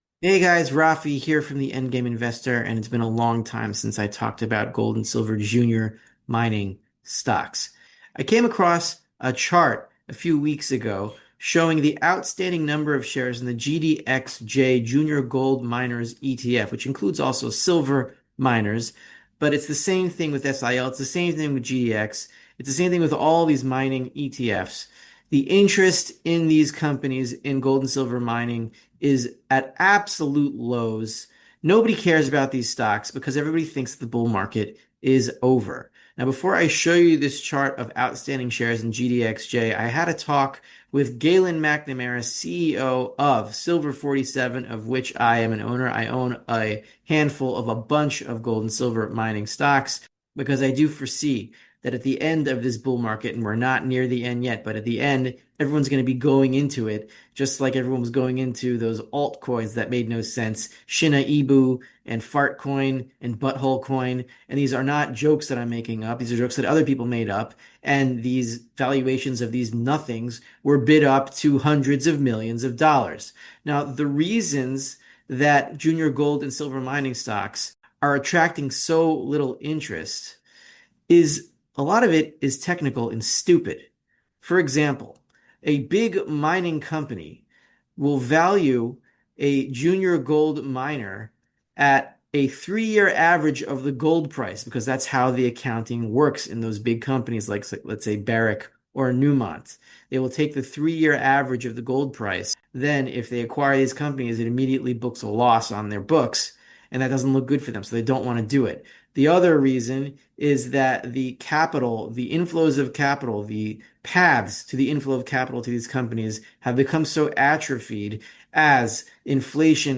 GDXJ Share Count Plummets As Juniors Completely Ignored - Interview